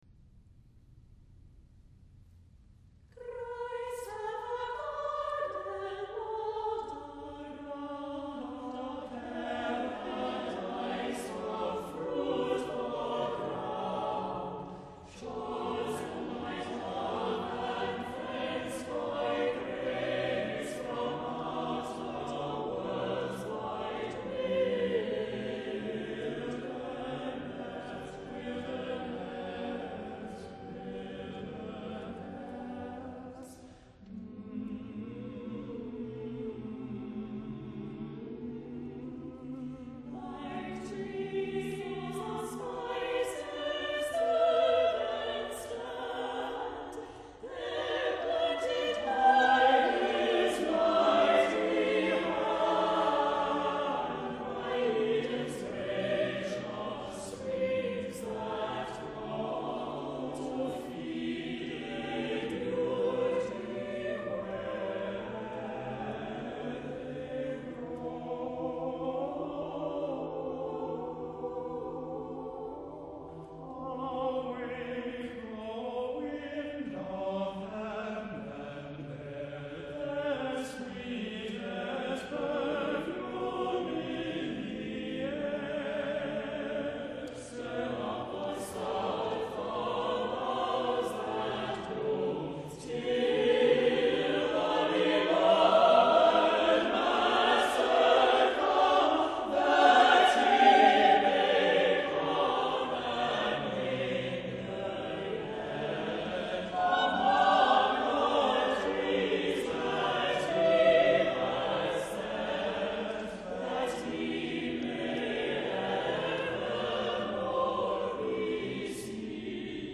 * gentle tune upon which all of the melodic lines are based